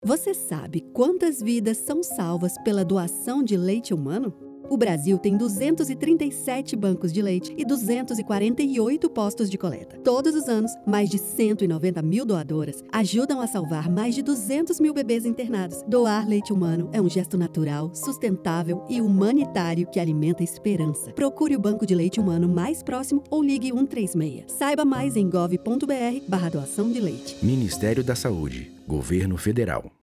Áudio - Spot 30s - Doação de leite - 1.1mb .mp3 — Ministério da Saúde